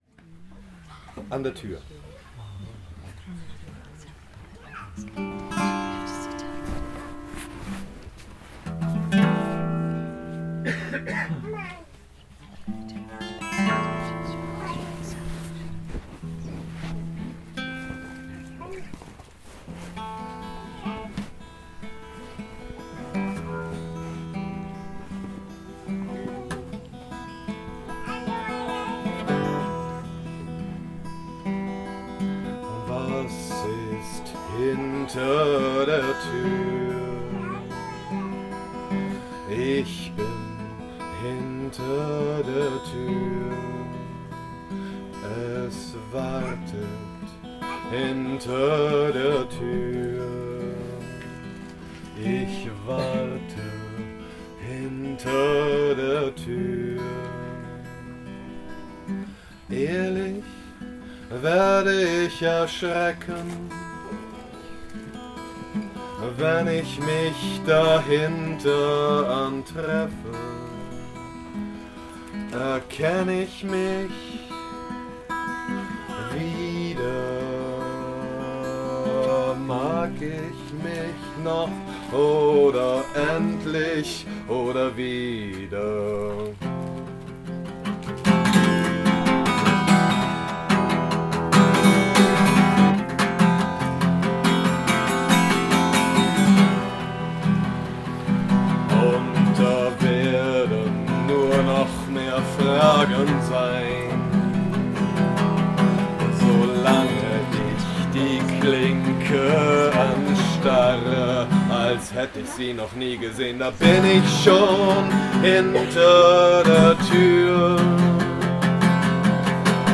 live in Holy Brook, Meadows, Devon, England